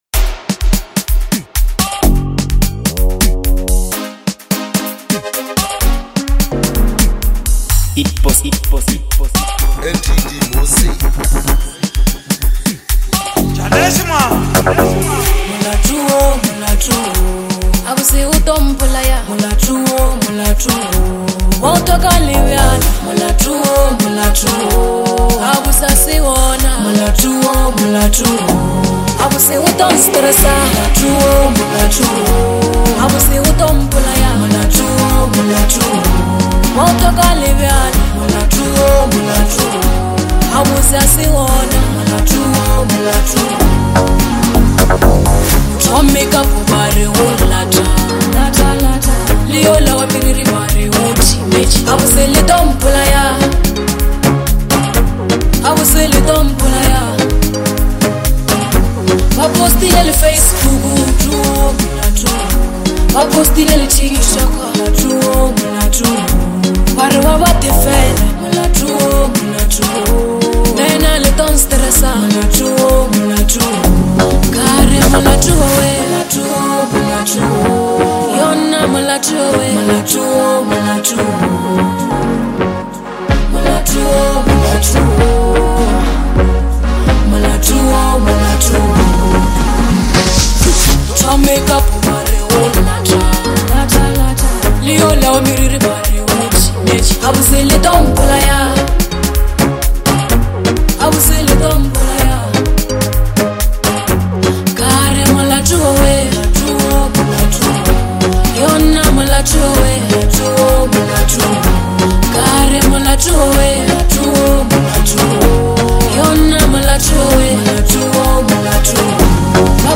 Amapiano, Hip Hop